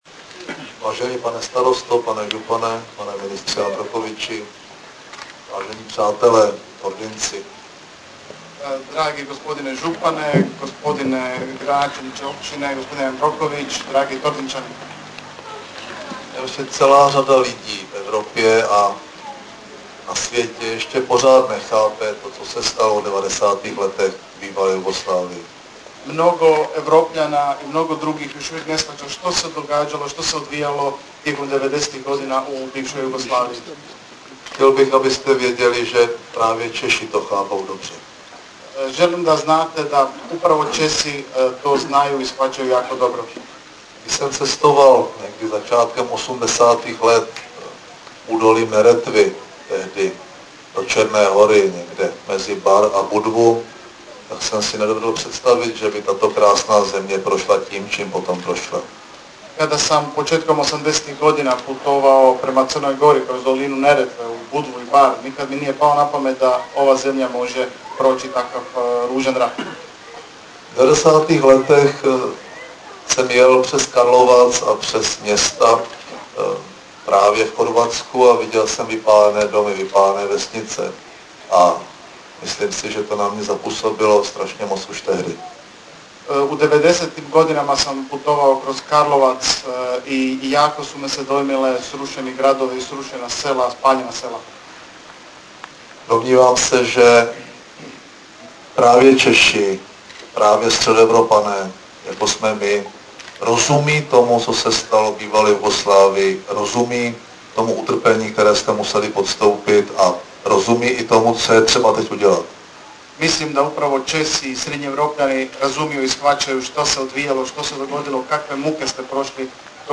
Projev premiéra M. Topolánka u příležitosti předání finančních prostředků na odminování chorvatských polí
Zvukový záznam projevu předsedy vlády M. Topolánka